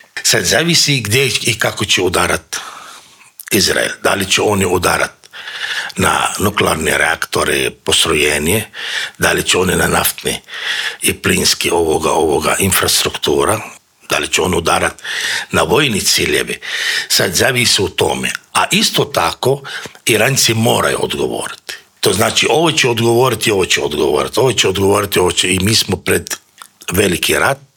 U studiju Media servisa